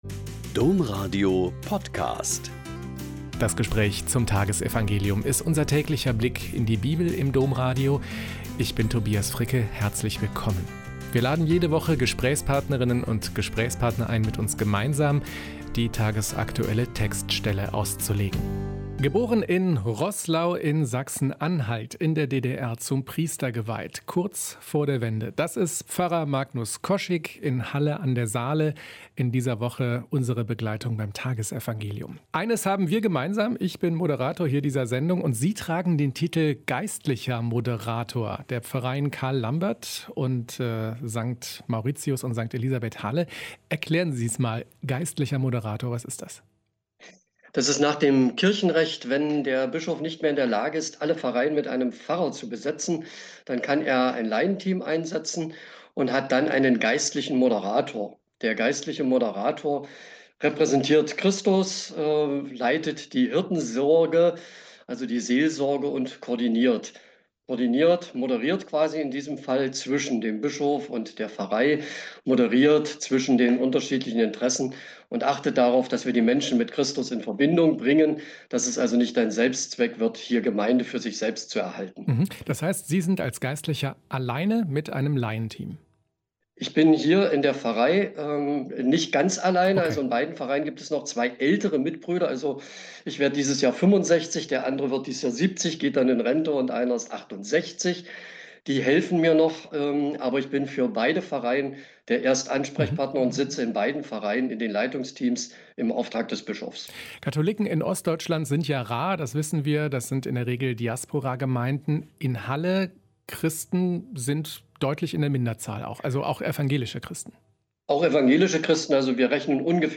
Mk 6,53-56 - Gespräch